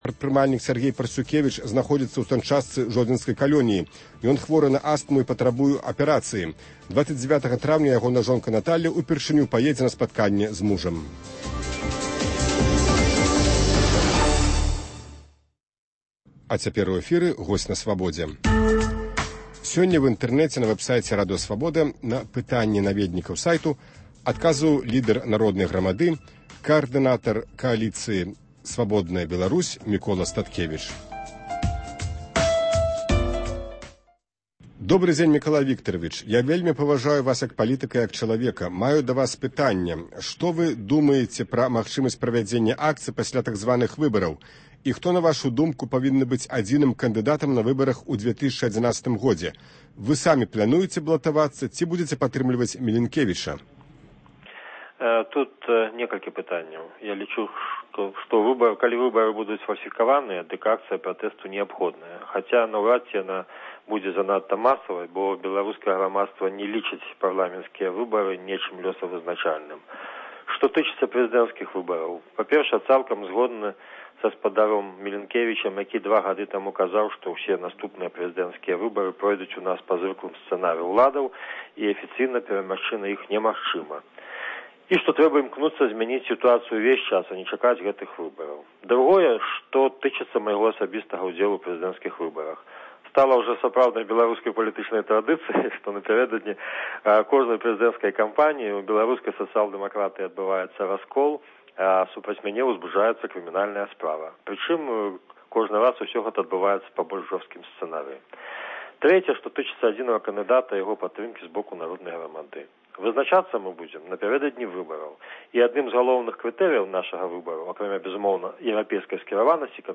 Онлайн- канфэрэнцыя
Онлайн-канфэрэнцыя з каардынатарам Эўрапэйскай кааліцыі “Свабодная Беларусь” Міколам Статкевічам